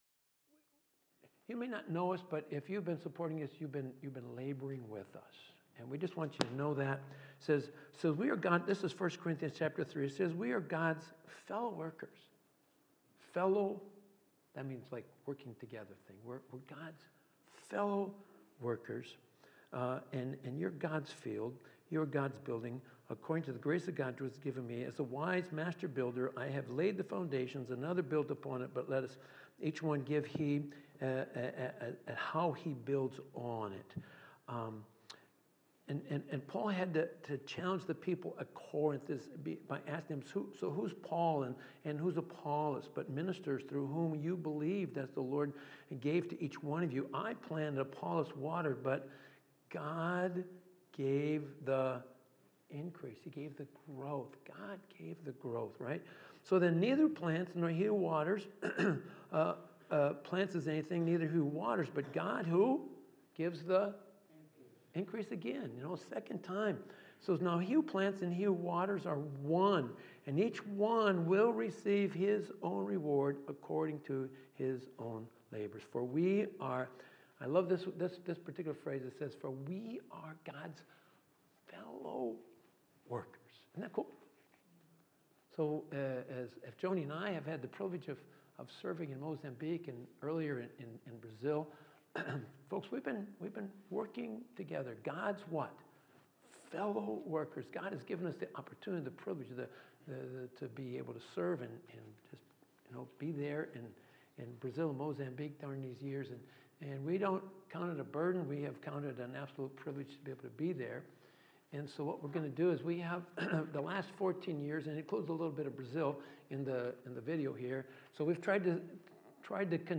Missionary Passage: Matthew 4:12-22 Service Type: Sunday Morning Service A message from one of our missionaries